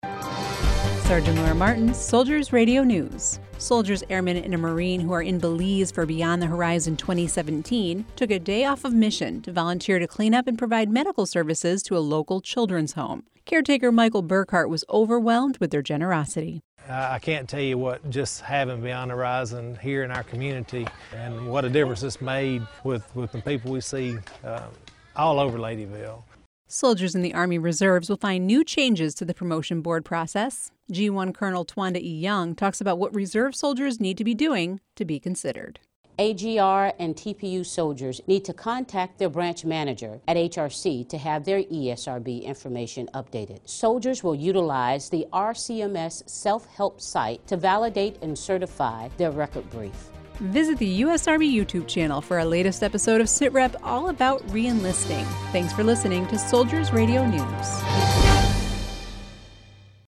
Soldiers Radio News